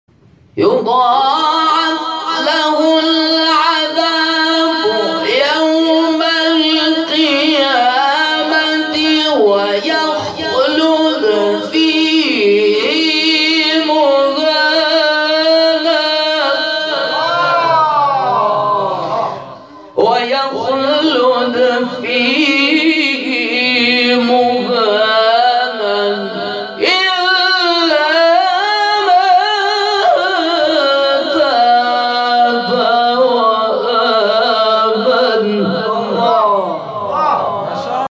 گروه شبکه اجتماعی: مقاطعی صوتی از تلاوت قاریان ممتاز کشور ارائه می‌شود.